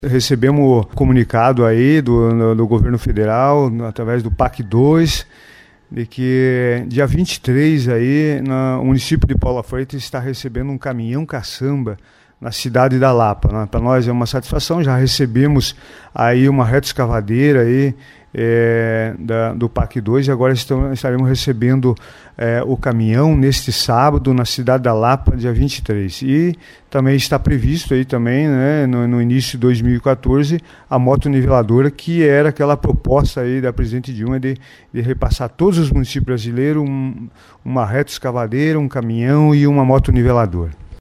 O prefeito Mauro Feliz dos Santos comenta sobre o FPM e diz que o município de Paula Freitas, tem que ter o apoio do Fundo de Participação dos Municípios para continuar a se desenvolver.